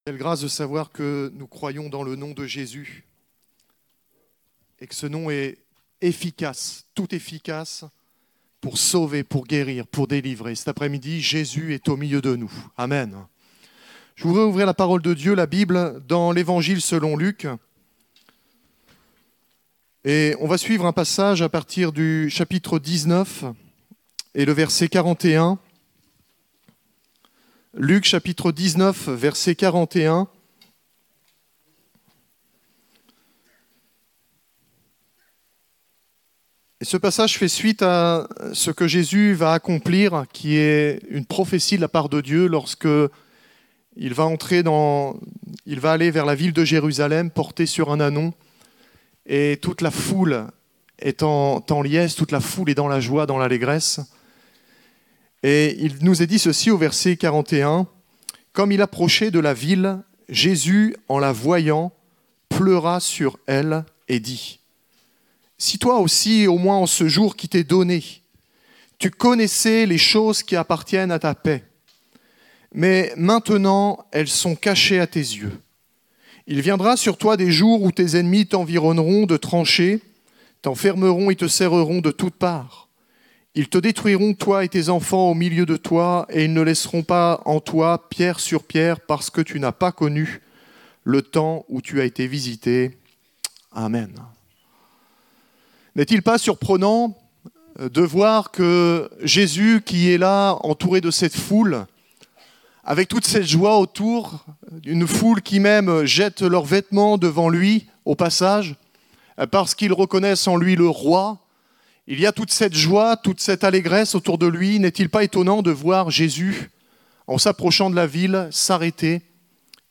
Découvrez en replay vidéo le message apporté à l'Eglise Ciel Ouvert